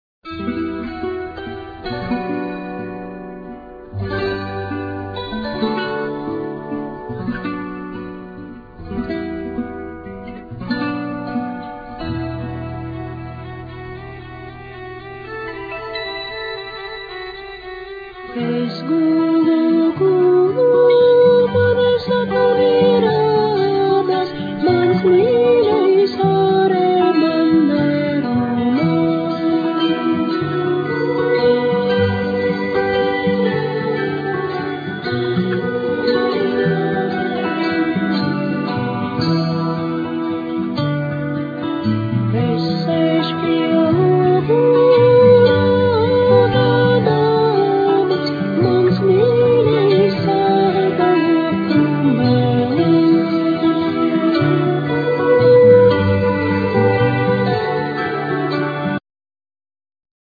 Vocal,Violin
Bagpipe,Accordion,Vocals
Kokle,Keyboards,Kalimba,China flute,Vocals
Acoustic & Electric guitars
Fretless,Acoustic & Double bass